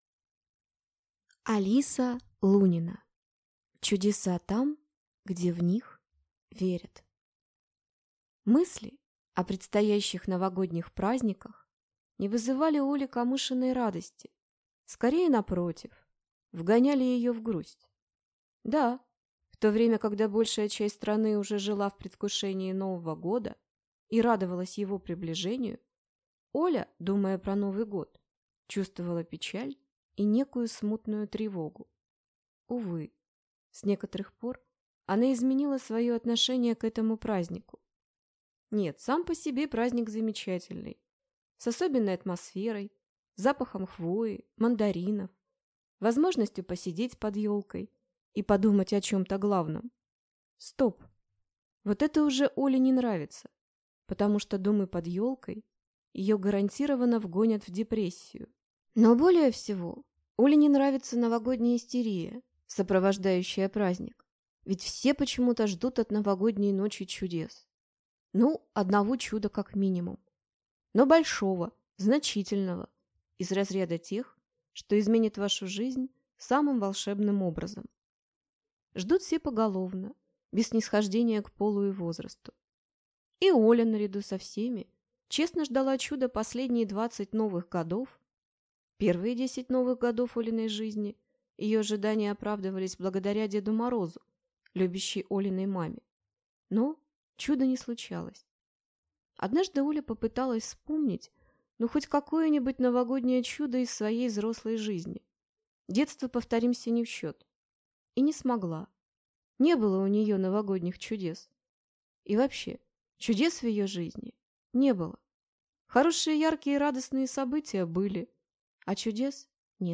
Аудиокнига Чудеса там, где в них верят | Библиотека аудиокниг